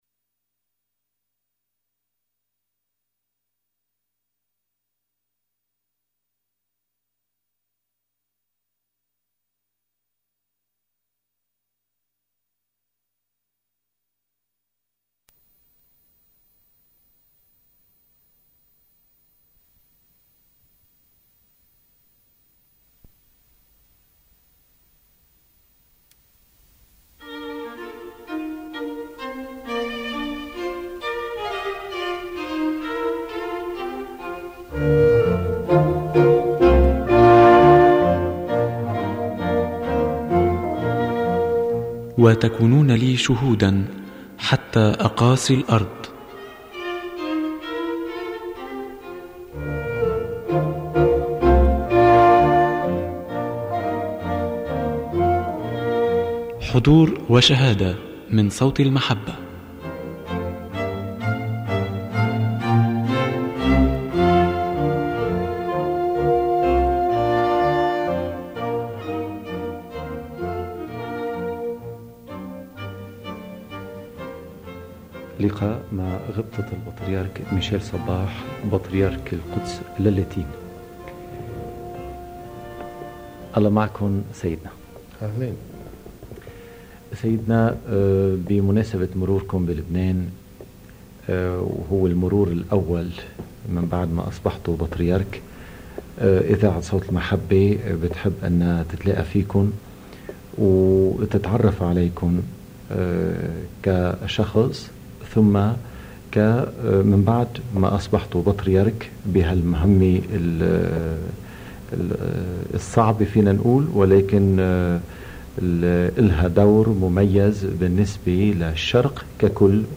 حضور وشهادة حديث مع البطريرك ميشال صبّاح للاتين Feb 13 2026 | 00:32:07 1x 00:00 / 00:32:07 Subscribe Share RSS Feed Share Link Embed